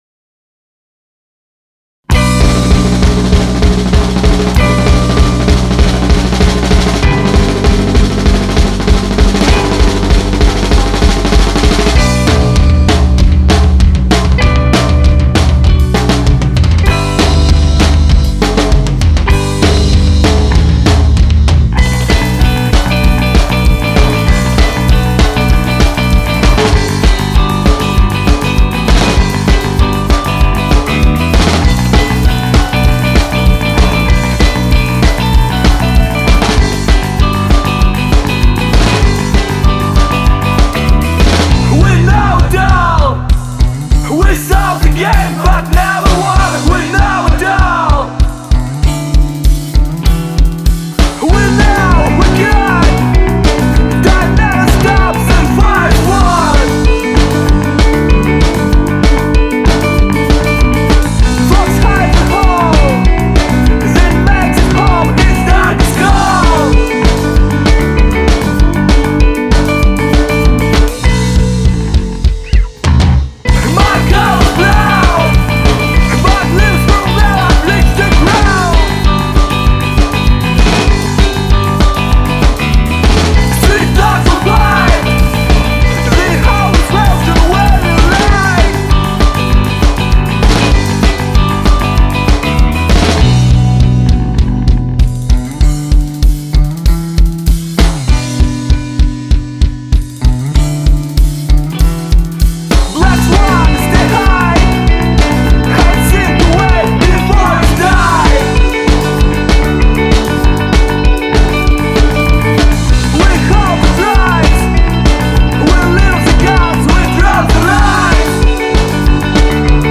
Genre: Indie Rock / Dance Rock / Math Rock